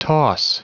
Prononciation du mot toss en anglais (fichier audio)
Prononciation du mot : toss